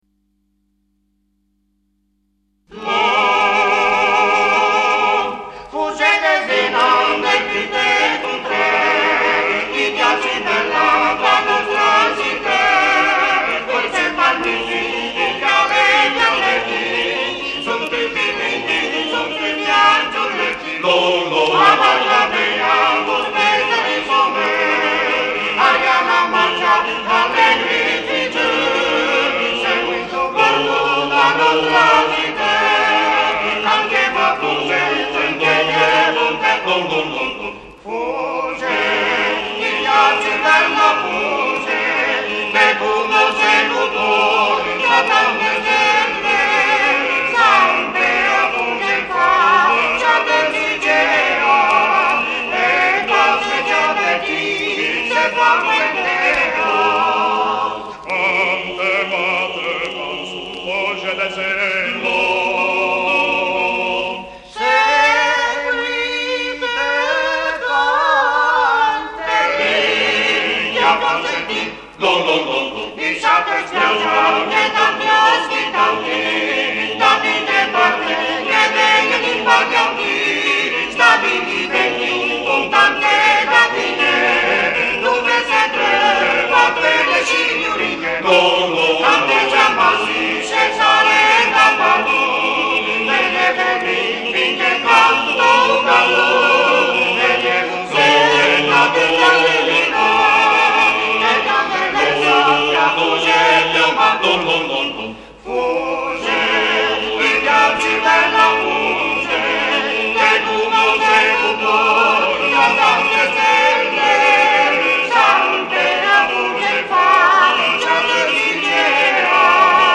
RACCOLTA DI CANTI TRADIZIONALI E SCENE COMICHE